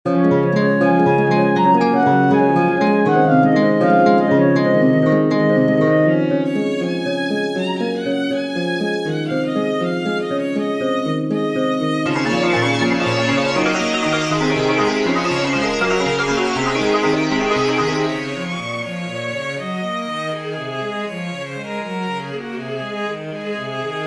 The MP3 of stochastic version.